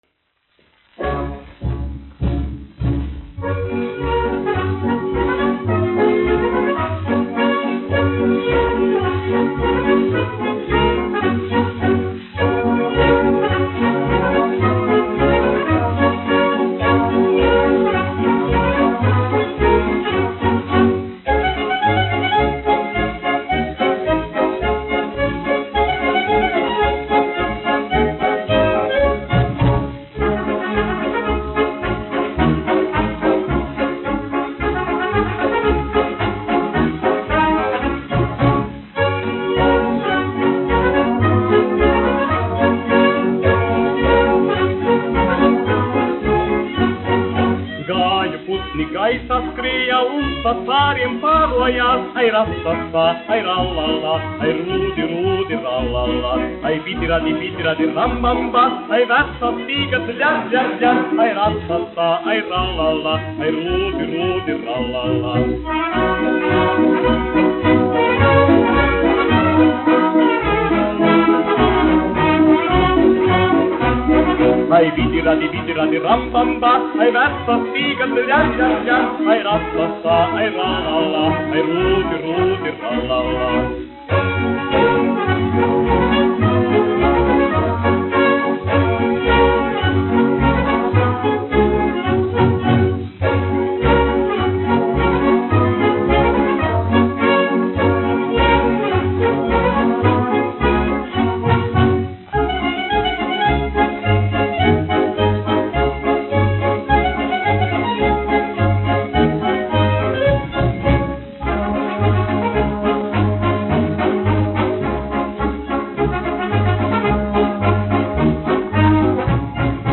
1 skpl. : analogs, 78 apgr/min, mono ; 25 cm
Polkas
Tautas deju mūzika -- Latvija
Latvijas vēsturiskie šellaka skaņuplašu ieraksti (Kolekcija)